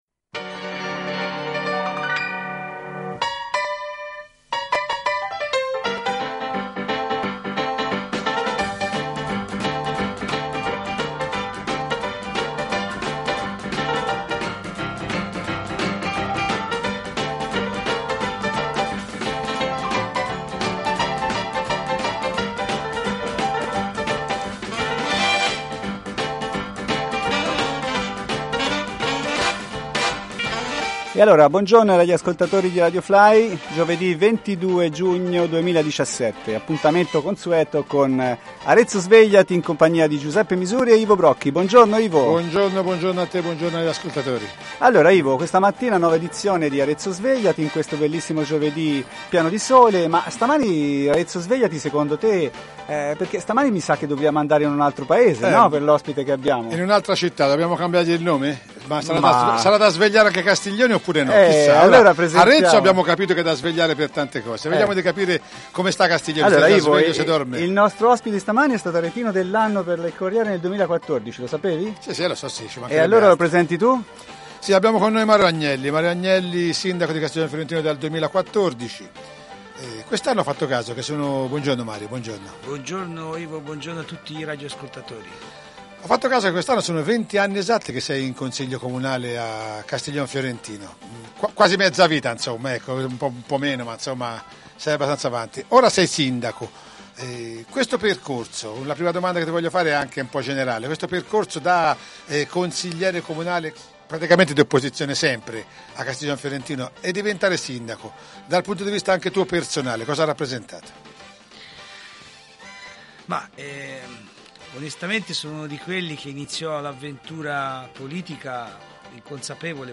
“Arezzo Svegliati” 15° Puntata Parla il Sindaco di Castiglion Fiorentino Mario Agnelli – RadioFly